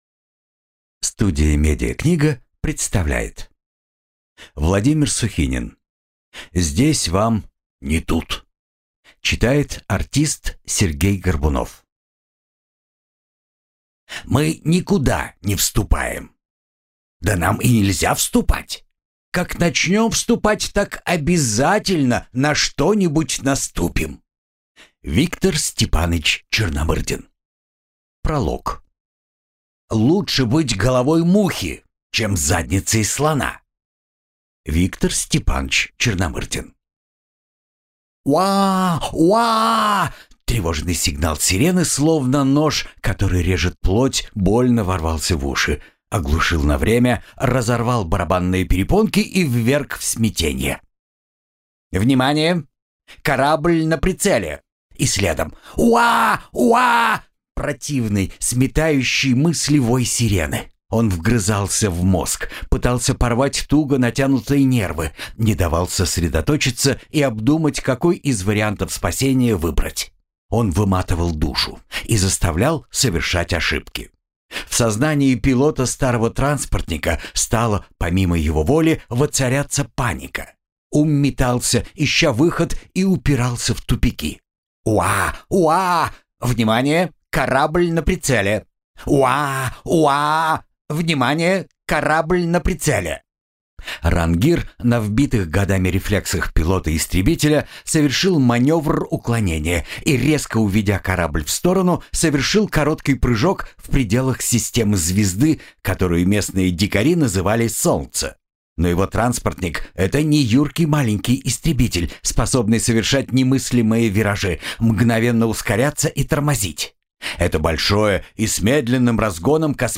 Аудиокнига Здесь вам не тут | Библиотека аудиокниг
Прослушать и бесплатно скачать фрагмент аудиокниги